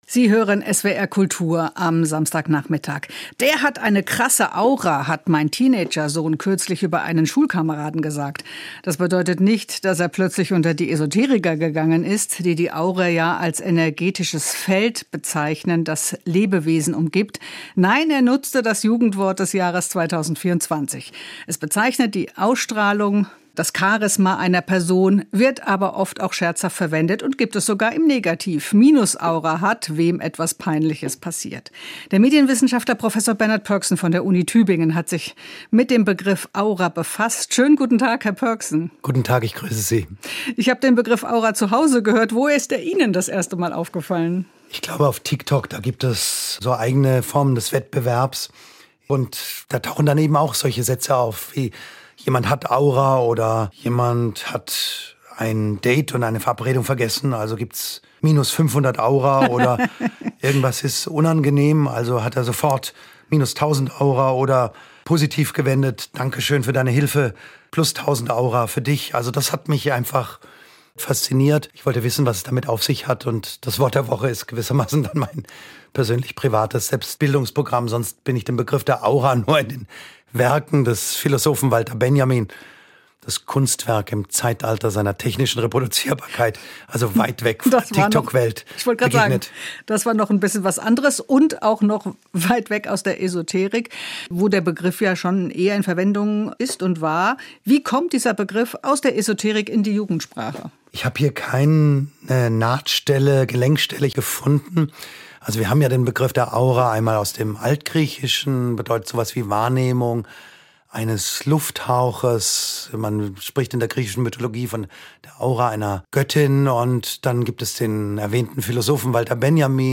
Gespräch mit